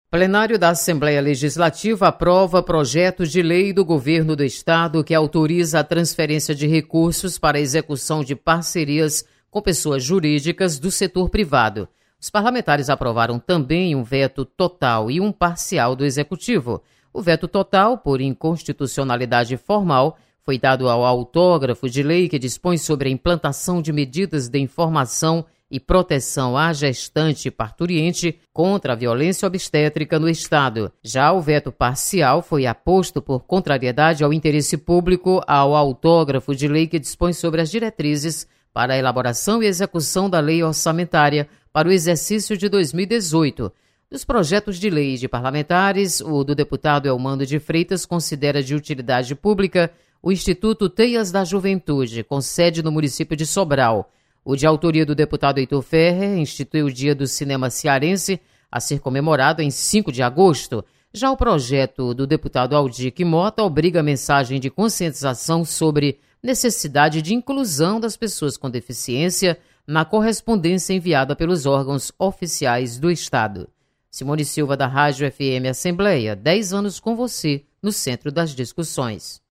Você está aqui: Início Comunicação Rádio FM Assembleia Notícias Veto